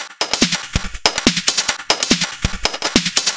Index of /musicradar/uk-garage-samples/142bpm Lines n Loops/Beats